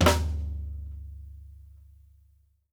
SNARE+FLOO-L.wav